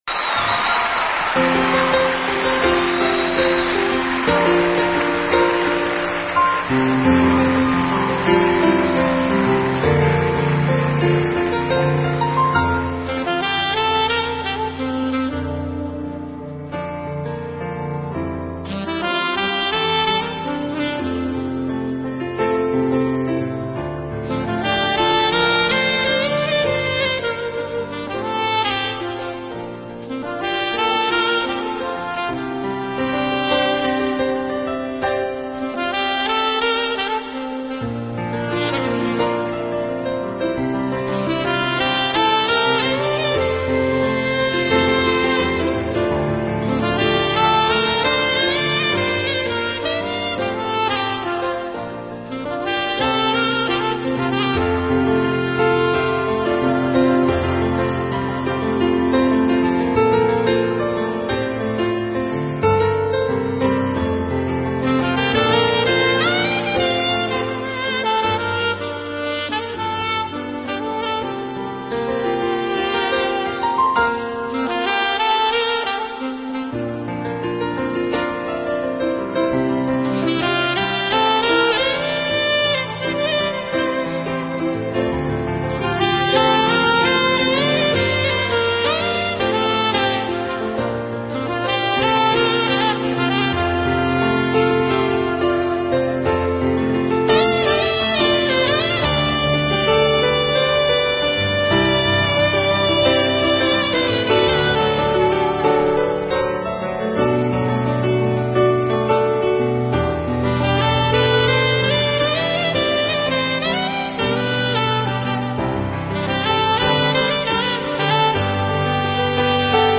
音乐类型：NewAge
音乐风格：新世纪